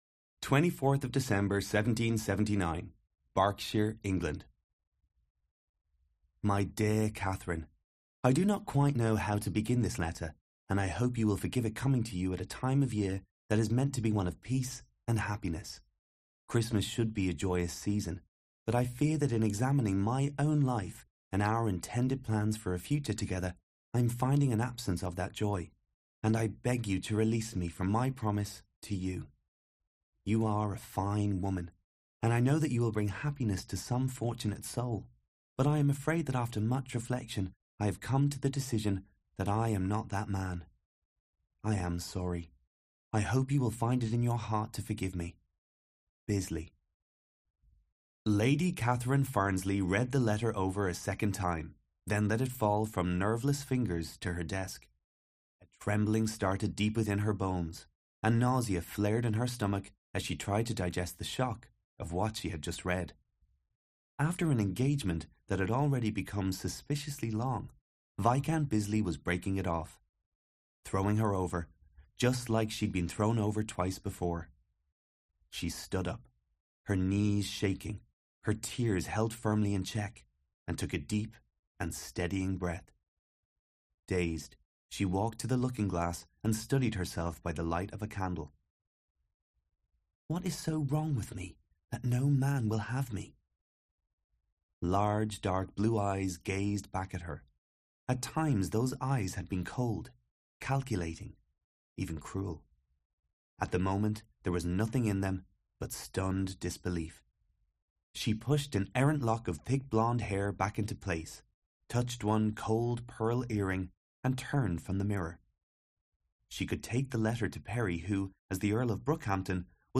Sprechprobe: eLearning (Muttersprache):
My-First-Noel-Audiobook.mp3